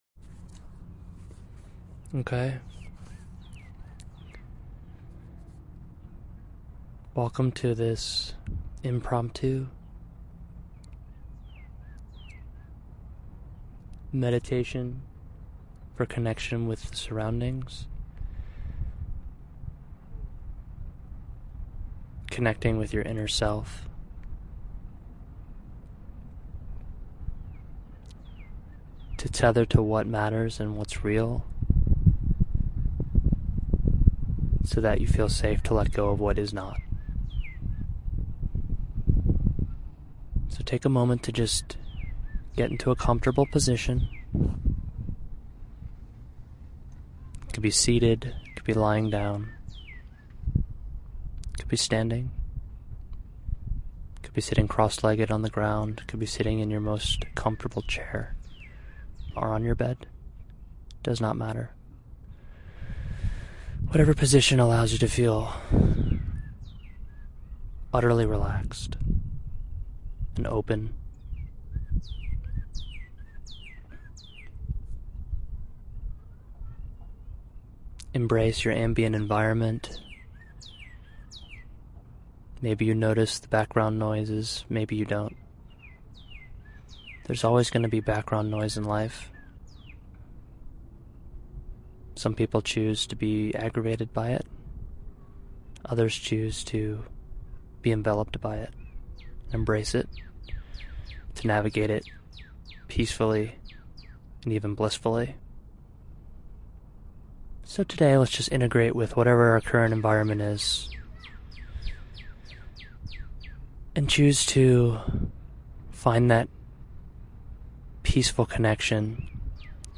Join me for a guided meditation for connection with oneself and one’s environment (both physically and spiritually.)